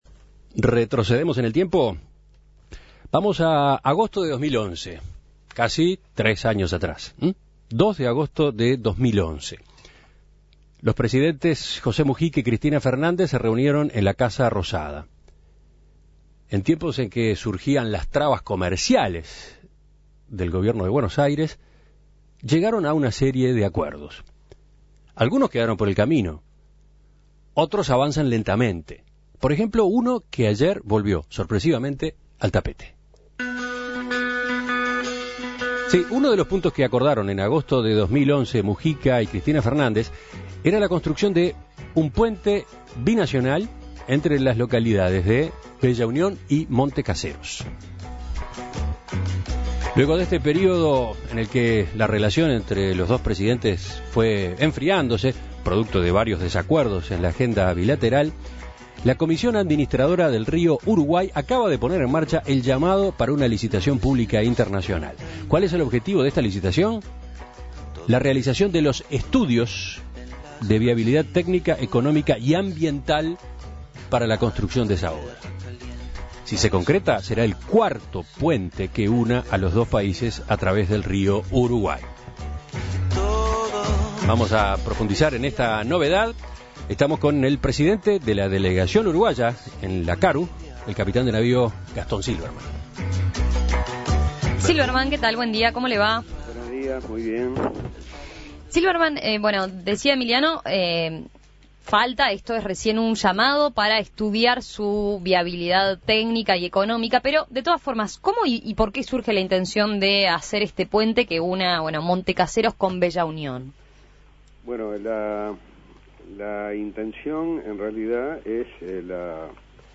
Para conocer más sobre este nuevo proyecto que unirá las dos orillas, En Perspectiva conversó con el capitán de Navío Gastón Silberman, presidente de la delegación uruguaya en la CARU.